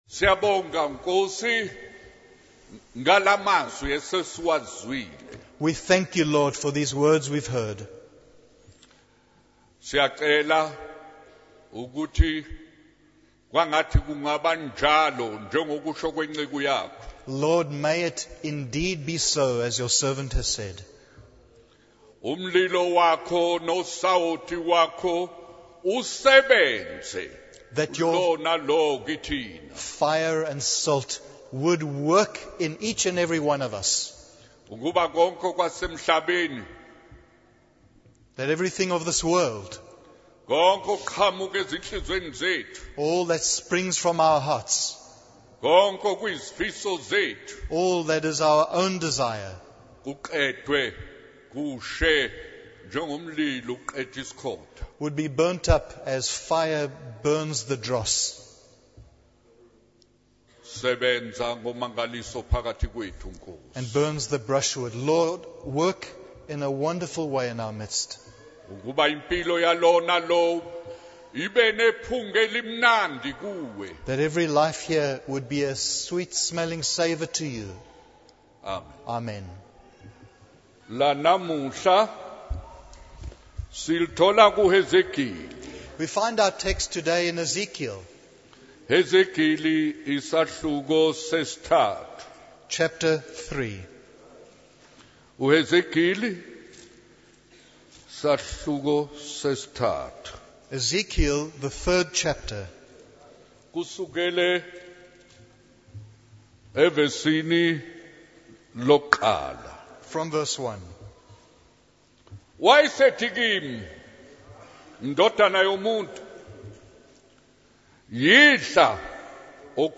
In this sermon, the preacher emphasizes the importance of studying and discussing the Word of God.